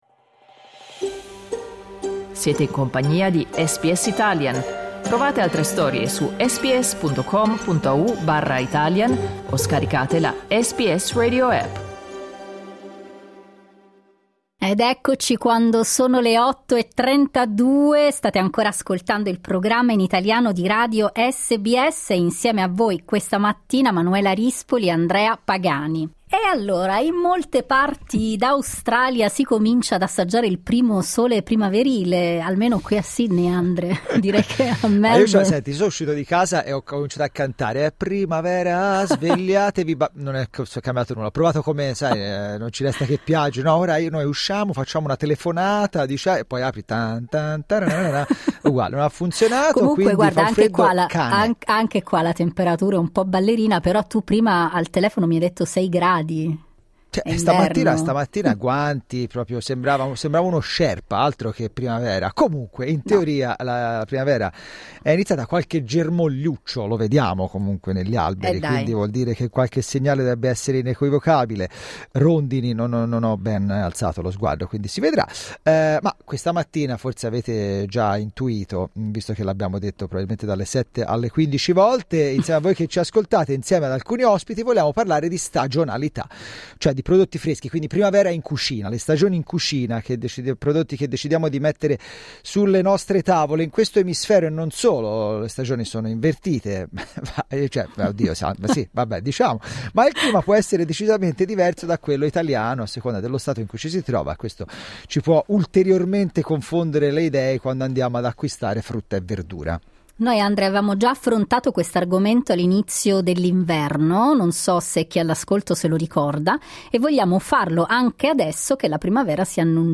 Cosa ci offre la terra in questa stagione dell'anno e in questa parte di mondo? Ecco i consigli di due chef italiani in Australia.